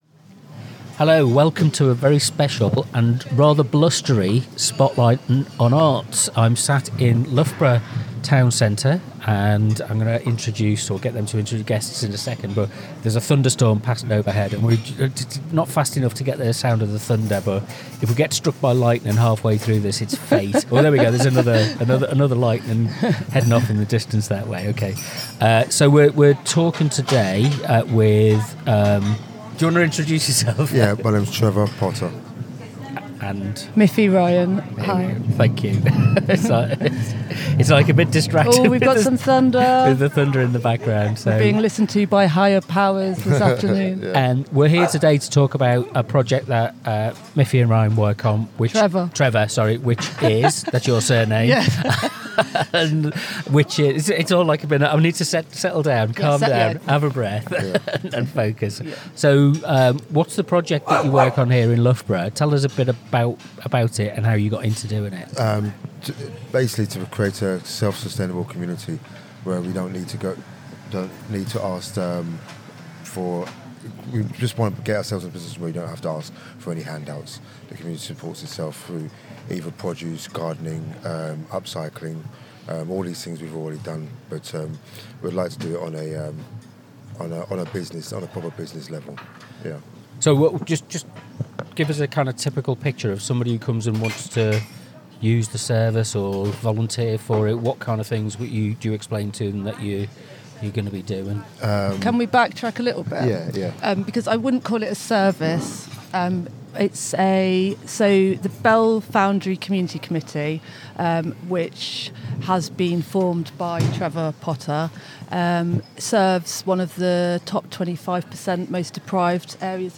This edition of Spotlight on Arts comes from Loughborough town centre